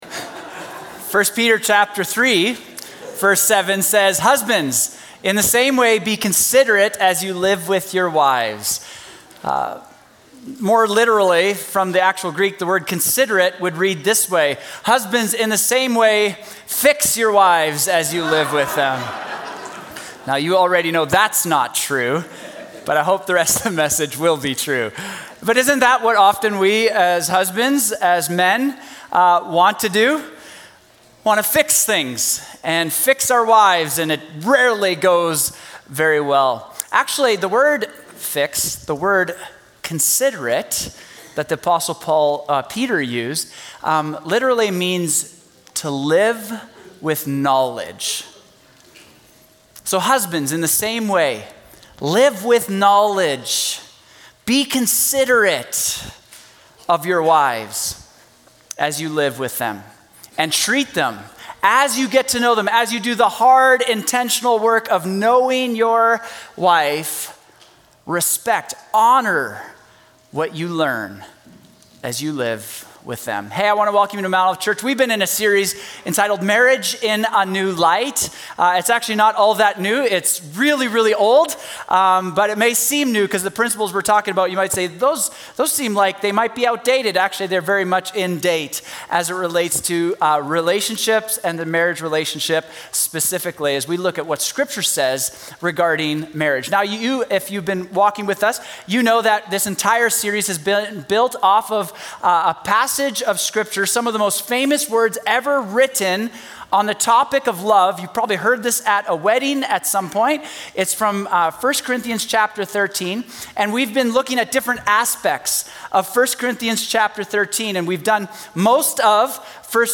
Sermons | Mount Olive Church
Real Life - Panel